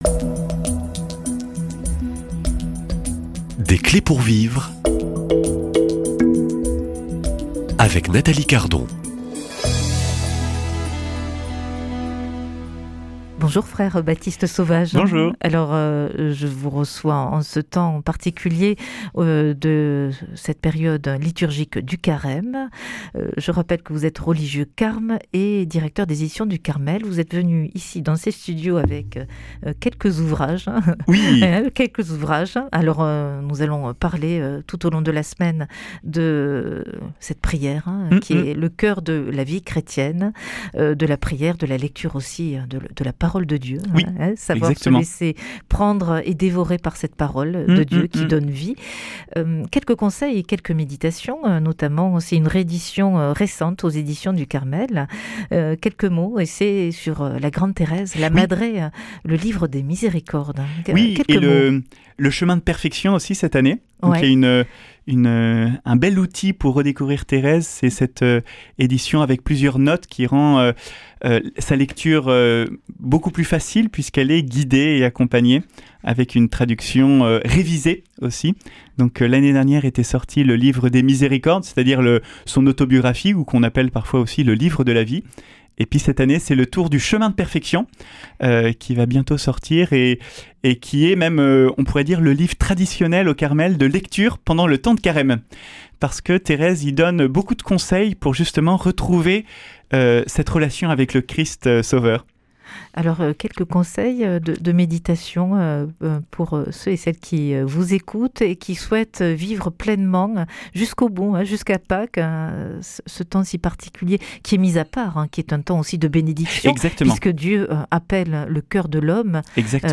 Invité : Religieux de l’ordre du Carmel